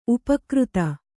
♪ upakřta